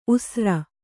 ♪ usra